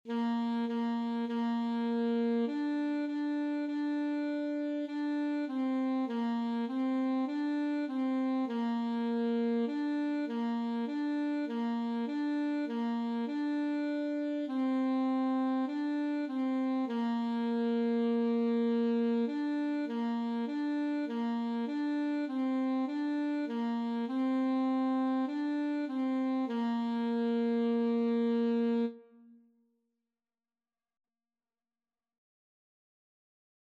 Free Sheet music for Alto Saxophone
4/4 (View more 4/4 Music)
Bb4-D5
Saxophone  (View more Beginners Saxophone Music)
Classical (View more Classical Saxophone Music)